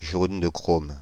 Ääntäminen
Synonyymit chromate de plomb Ääntäminen France (Île-de-France): IPA: /ʒon də kʁom/ Haettu sana löytyi näillä lähdekielillä: ranska Käännöksiä ei löytynyt valitulle kohdekielelle.